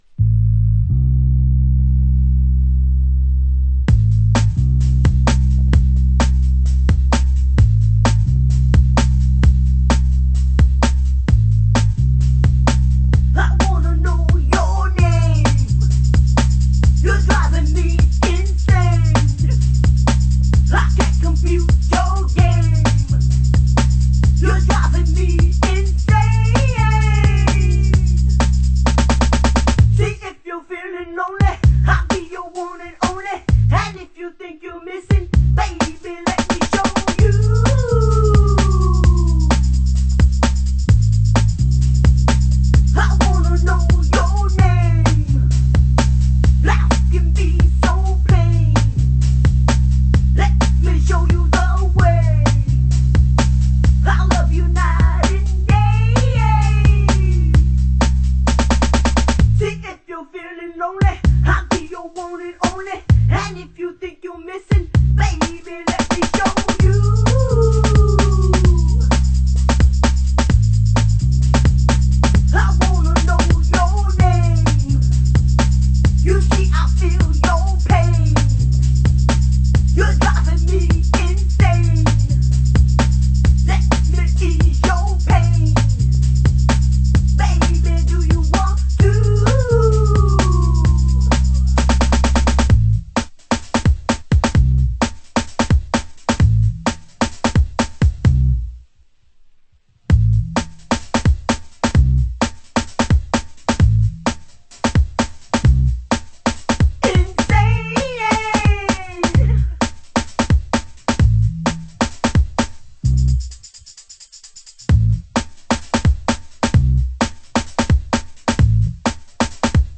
DISCO/FUNK